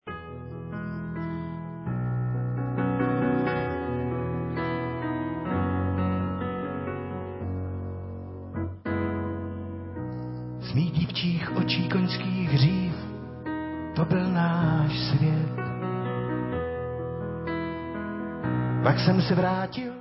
sledovat novinky v oddělení Mluvené slovo
sledovat novinky v oddělení Pop